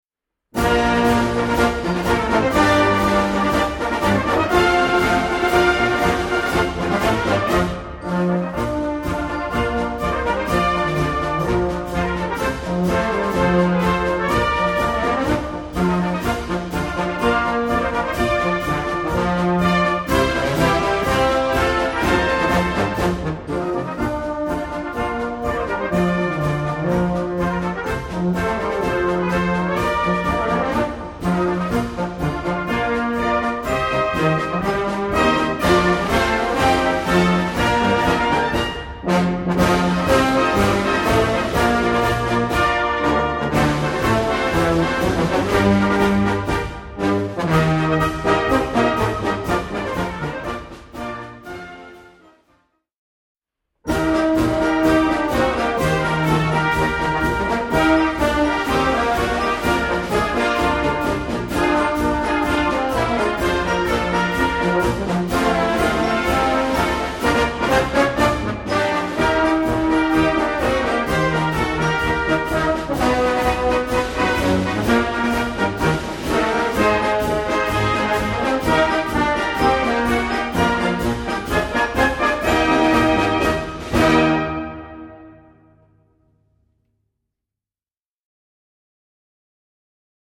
3:00 Minuten Besetzung: Blasorchester PDF